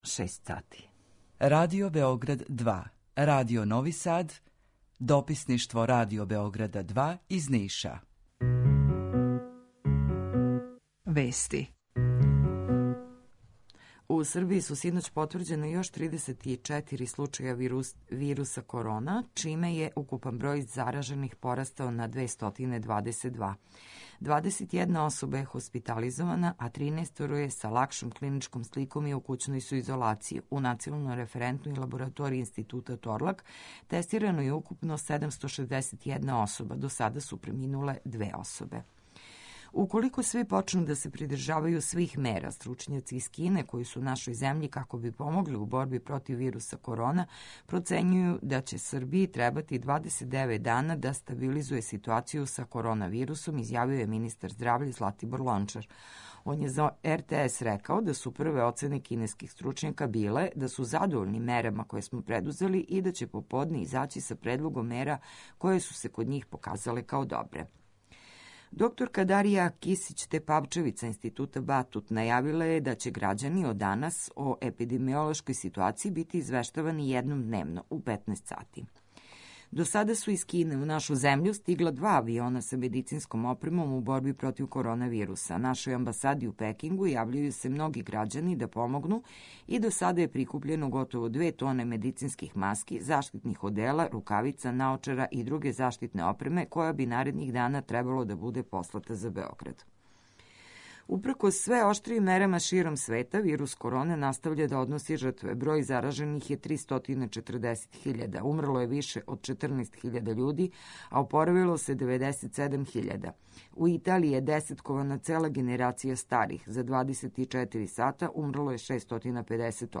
Јутарњи програм из три студија
Jутарњи програм заједнички реализују Радио Београд 2, Радио Нови Сад и дописништво Радио Београда из Ниша.